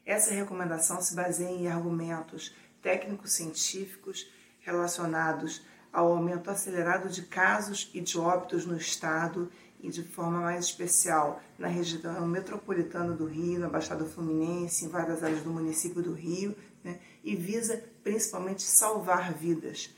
Em entrevista à Super Rádio Tupi